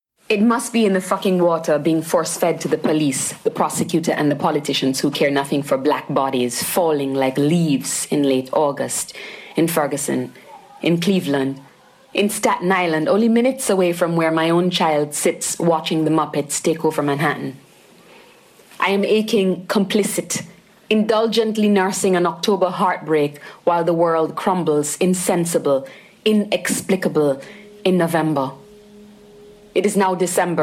Basse (instrument)